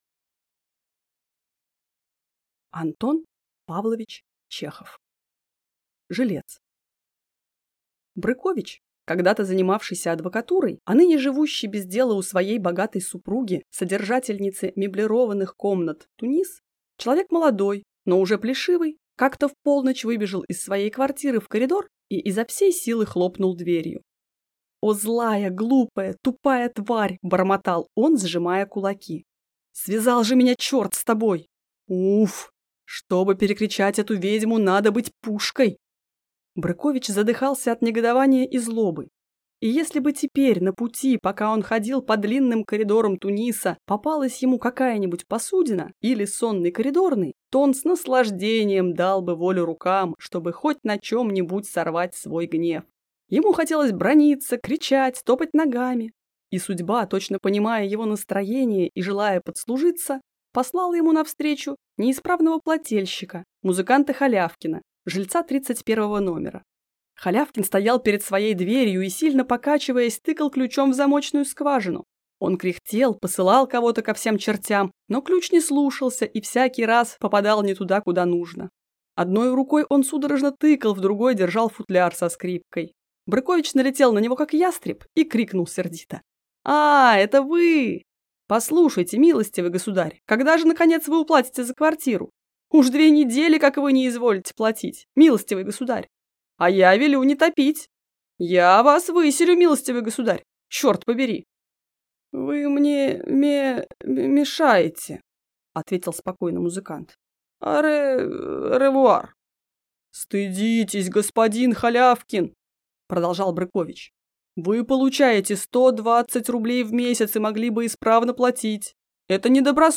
Aудиокнига Жилец